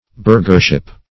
Burghership \Burgh"er*ship\, n. The state or privileges of a burgher.